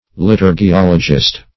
Liturgiologist \Li*tur`gi*ol"o*gist\, n. One versed in liturgiology.